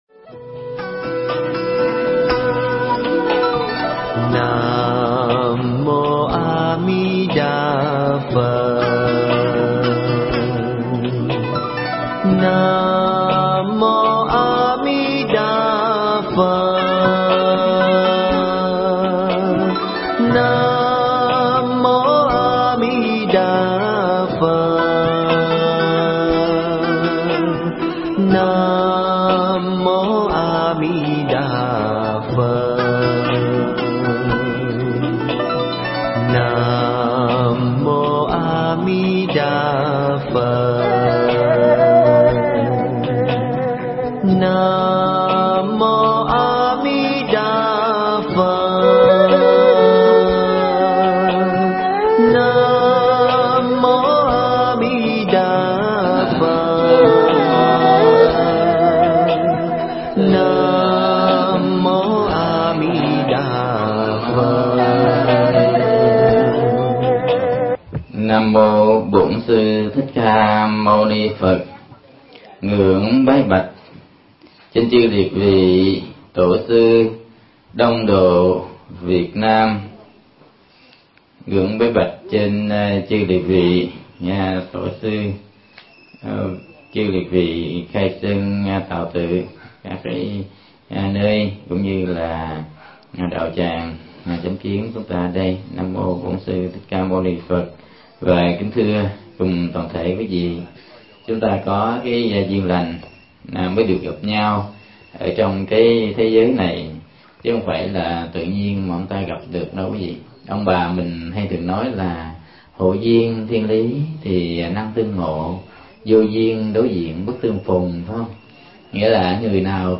Mp3 Thuyết Giảng Tôi Luyện Tâm Niệm Phật Đường Cứu Khổ
giảng tại Chùa Chánh Kiến, Bạc Liêu